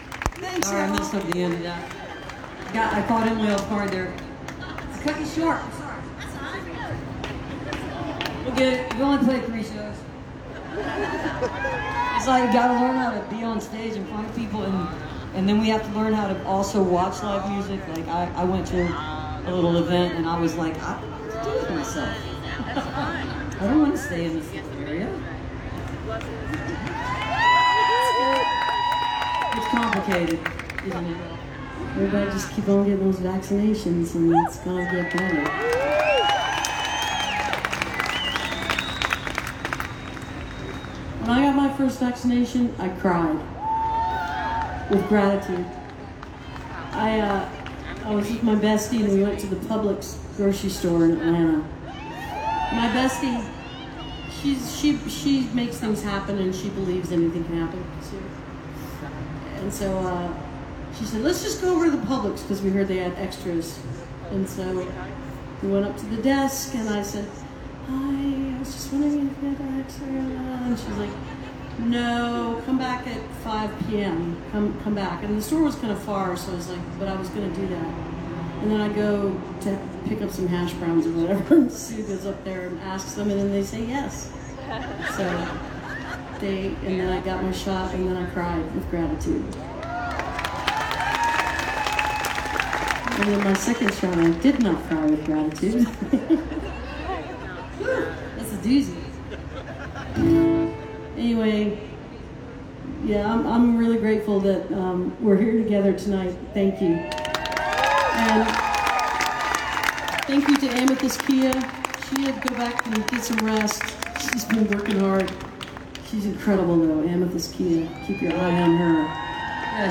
(captured from the facebook livestream)
18. talking with the crowd (2:16)